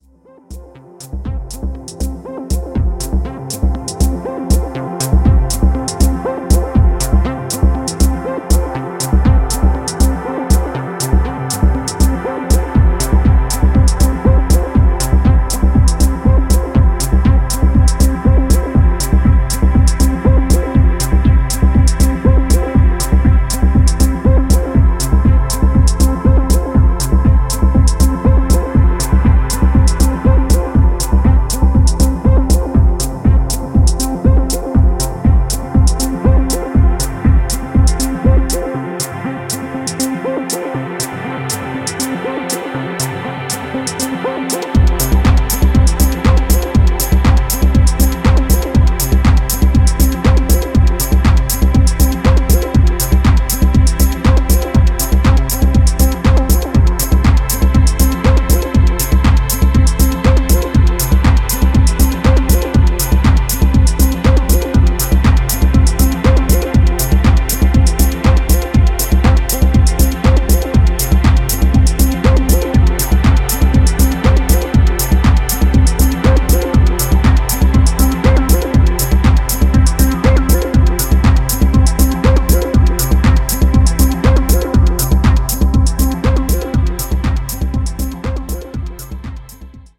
8 Smooth & French club/house tracks!